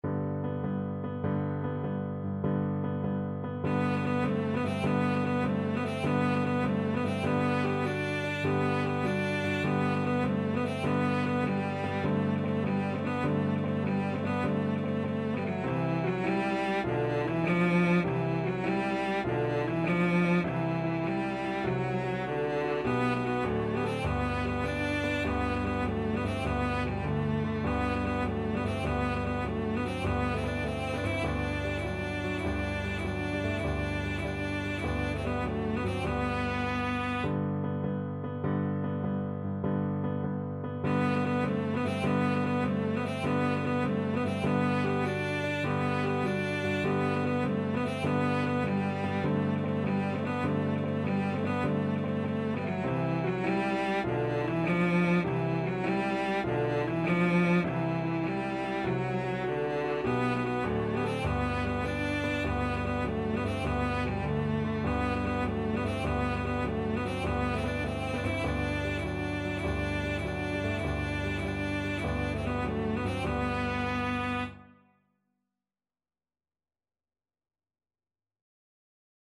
= 100 Allegro assai (View more music marked Allegro)
6/8 (View more 6/8 Music)
Cello  (View more Easy Cello Music)
Classical (View more Classical Cello Music)